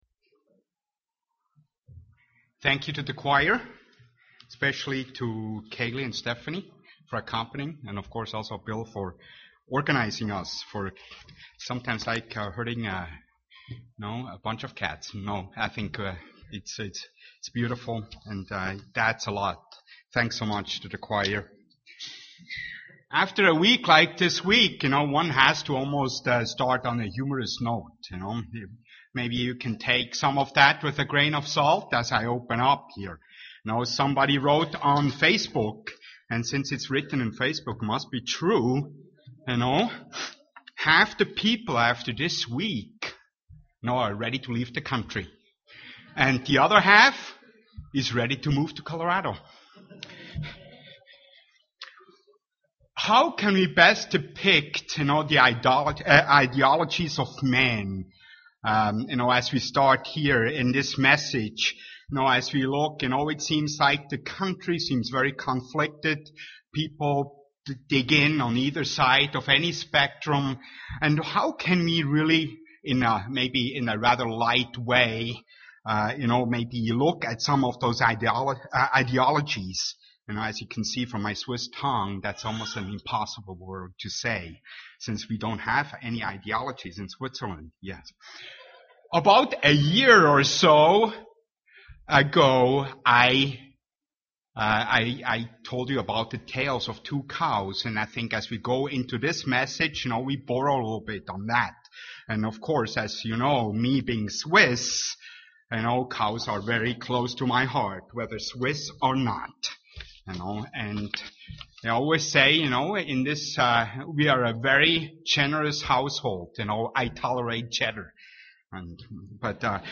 UCG Sermon Studying the bible?
Given in Twin Cities, MN